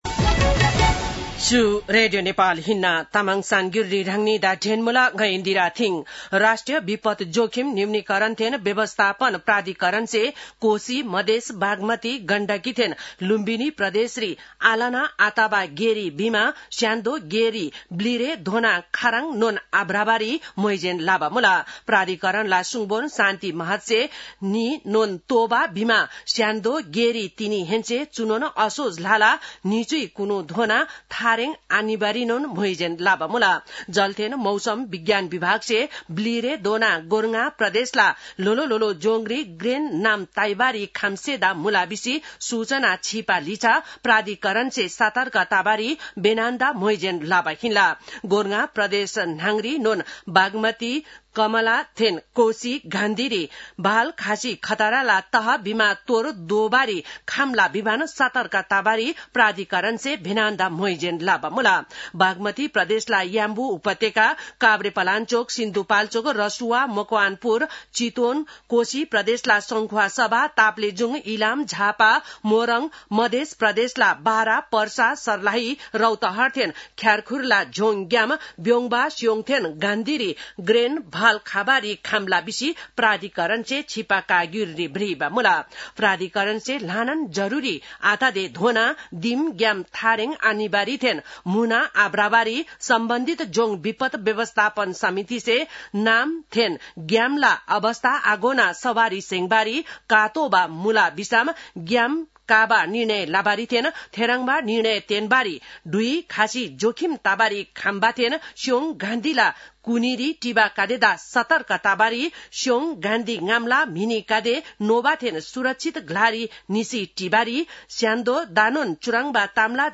तामाङ भाषाको समाचार : १७ असोज , २०८२